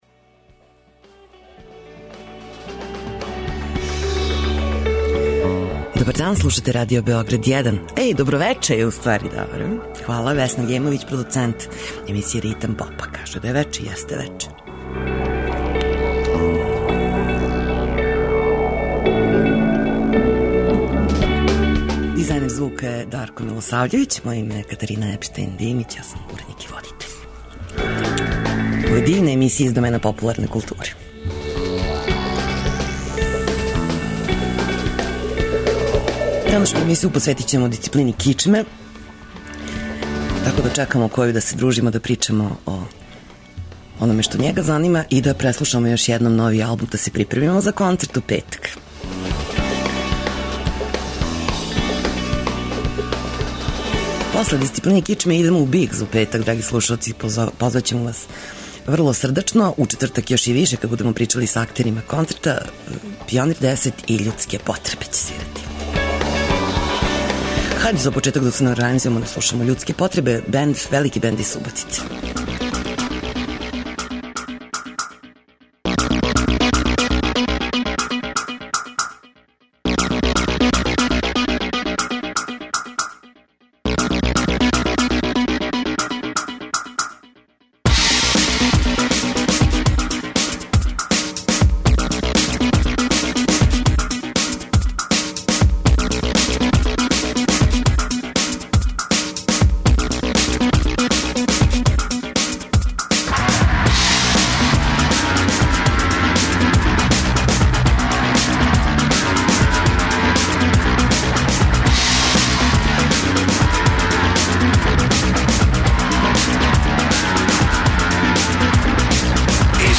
Наш гост је фронтмен групе Дисциплина Кичме - Душан Којић Која. Најавиће концерт који ће ова група одржати у петак и Дому омладине у Београду.